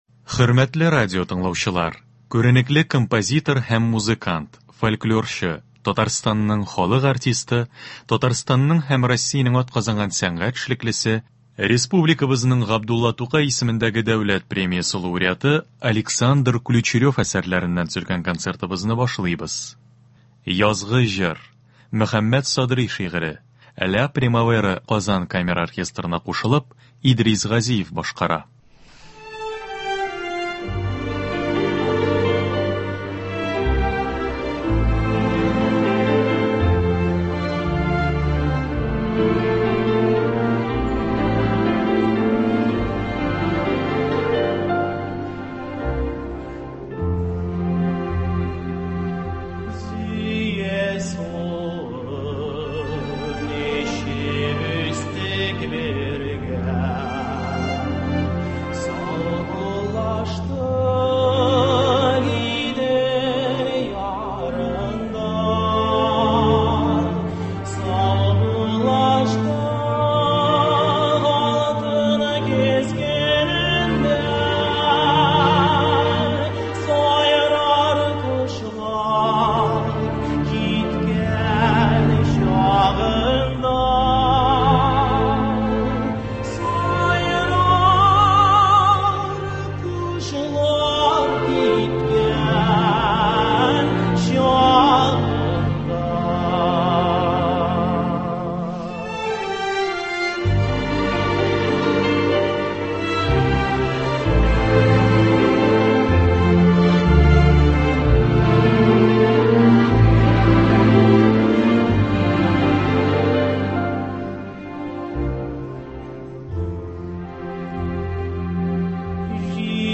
Концерт. 19 февраль.